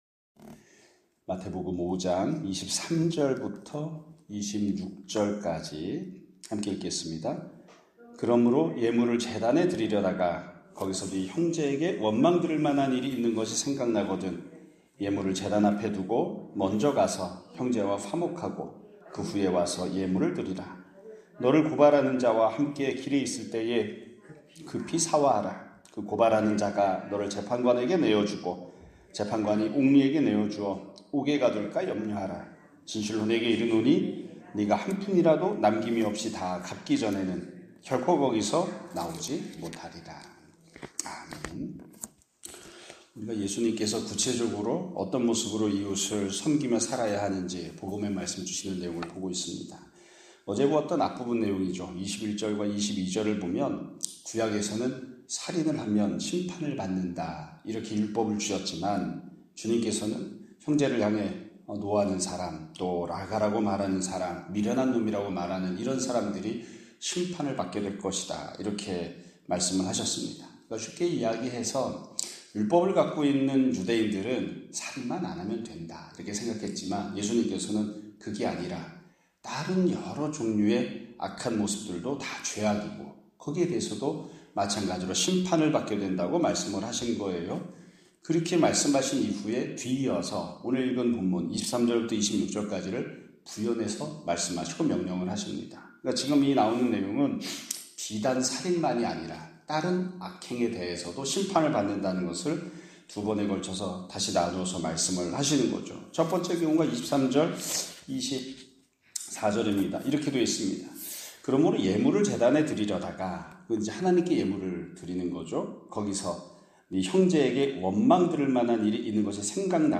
2025년 5월 23일(금요일) <아침예배> 설교입니다.